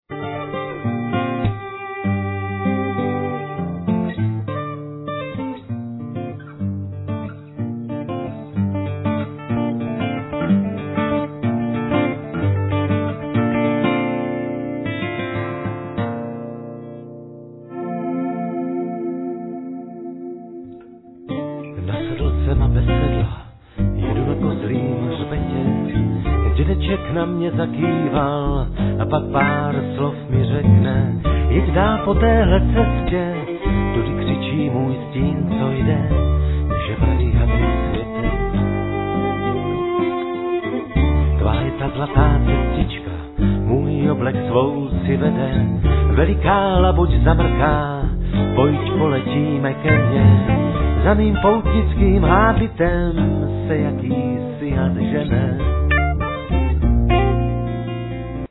Guitars, Voice, Percussions
Saxophone
Accordion
Viola